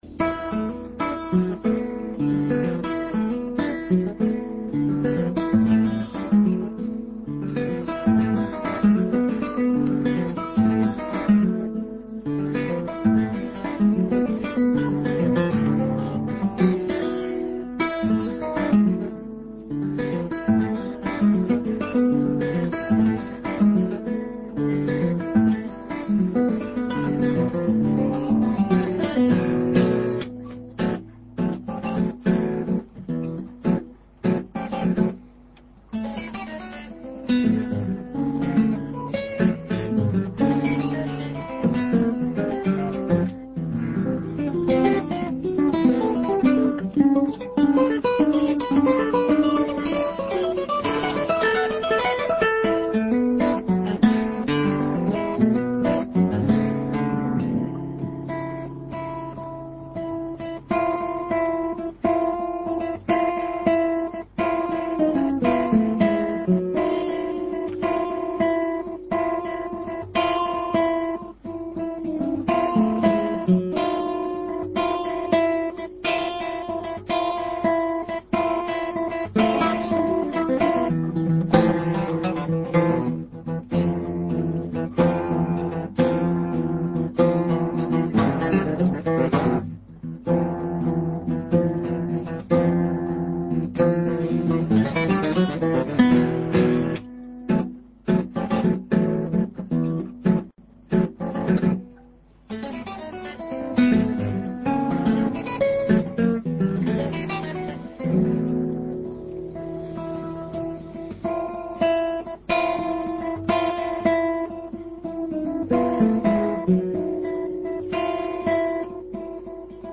Hicieron fonoplatea en los estudios del espectador, nos contaron su proyecto como dúo y tomaron un cafeto guitarrístico.